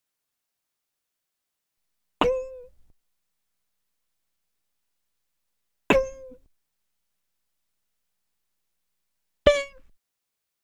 Category 🗣 Voices
bing human pop request vocal voice sound effect free sound royalty free Voices